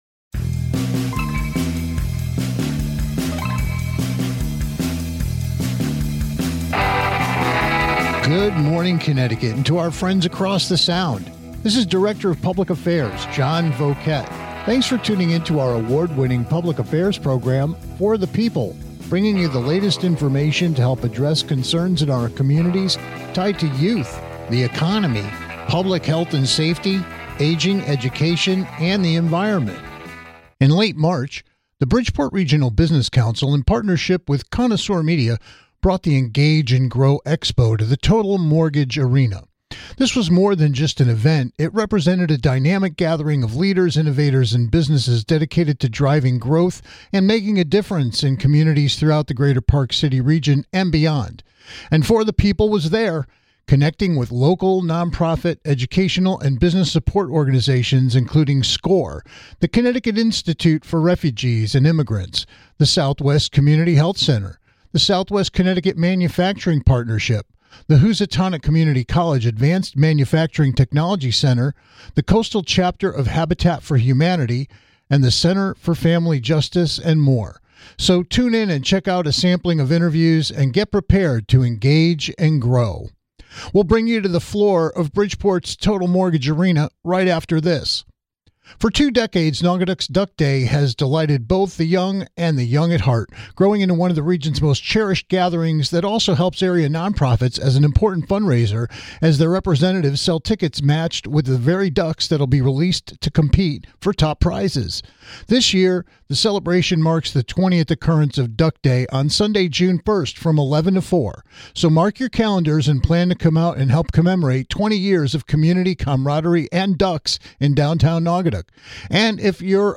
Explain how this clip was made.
In late March, the Bridgeport Regional Business Council hosted the Engage & Grow Expo and For the People was there - connecting with dynamic leaders from local nonprofit, educational, and business support organizations like SCORE, the CT Institute for Refugees and Immigrants, ...